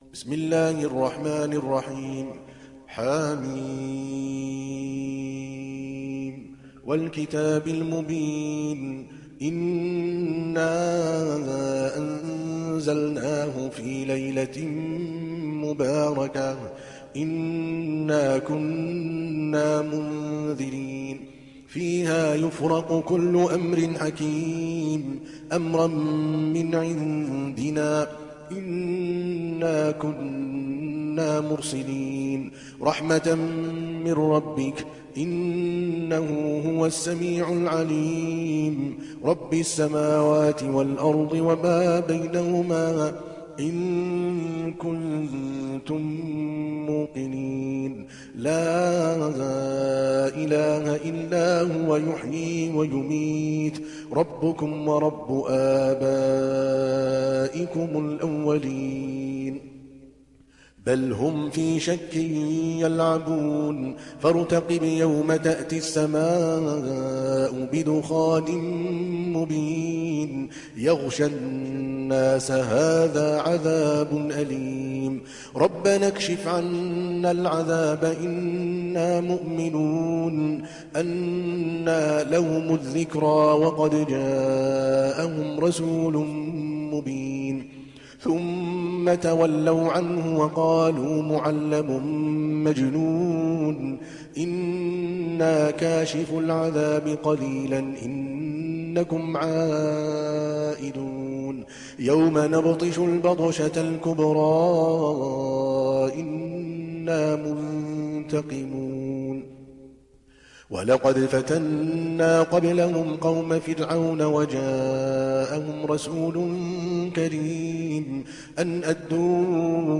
دانلود سوره الدخان mp3 عادل الكلباني روایت حفص از عاصم, قرآن را دانلود کنید و گوش کن mp3 ، لینک مستقیم کامل